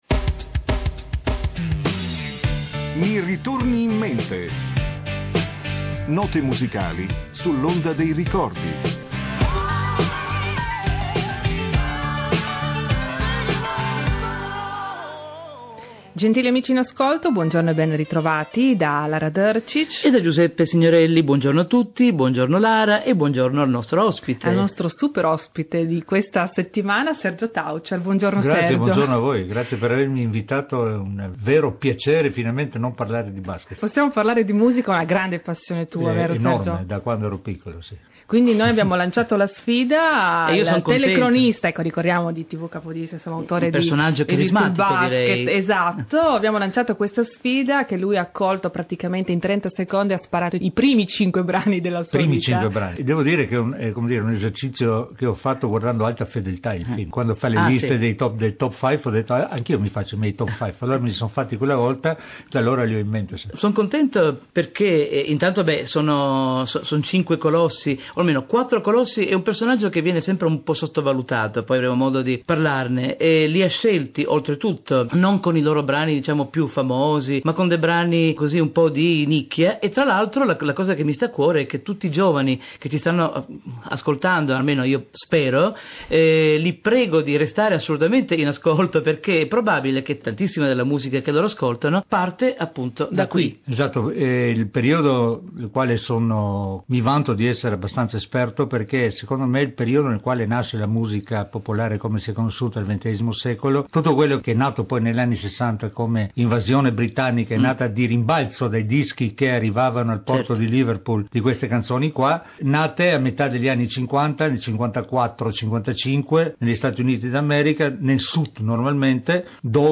Giorni fa sono stato ospite di una trasmissione di Radio Capodistria dal titolo " Mi ritorni in mente ", nella quale un personaggio noto (e io a Capodistria lo sono) presenta cinque brani musicali secondo lui significativi.